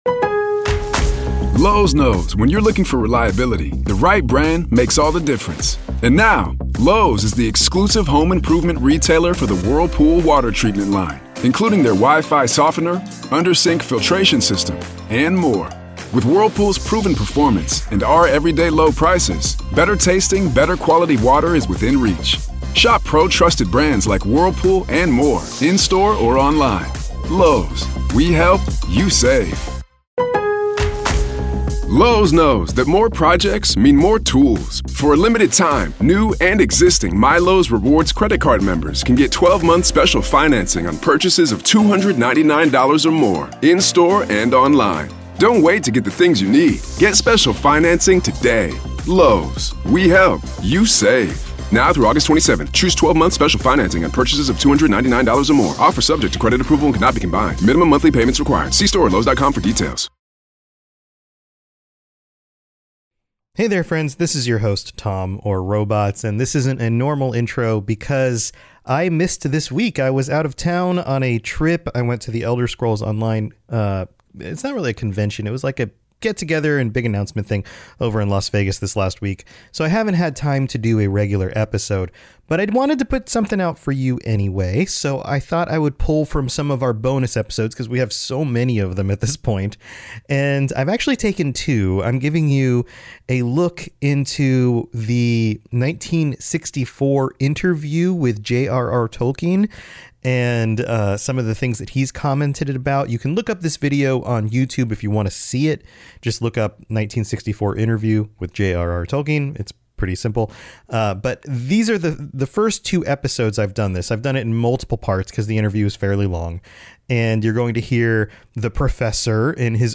I'm out of town this week, so here's a sneak peak at a really cool interview with JRR Tolkien from 1964 and my thoughts and comments from our Patreon bonus episodes.